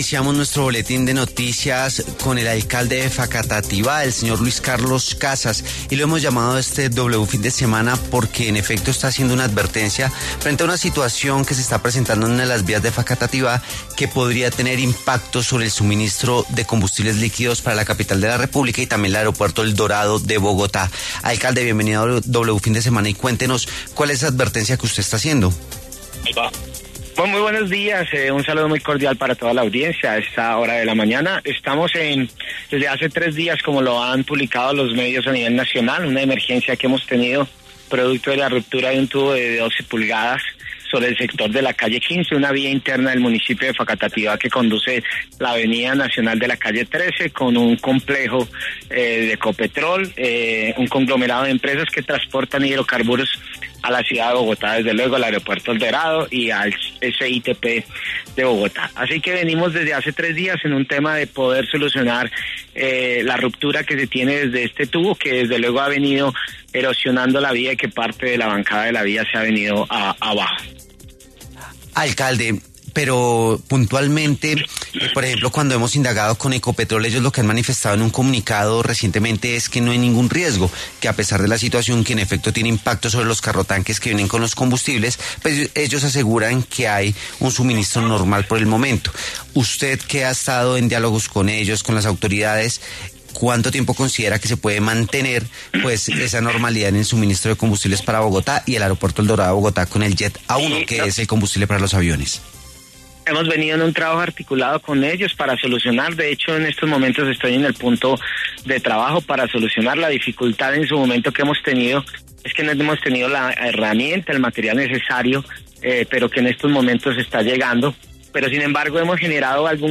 El alcalde de Facatativá, Luis Carlos Casas, pasó por los micrófonos de W Fin de Semana para hablar sobre la posibilidad de que Bogotá y el aeropuerto El Dorado se queden sin combustibles líquidos por daño en una vía de ese municipio donde transitan los carrotanques que salen de la plata principal de combustibles de Ecopetrol.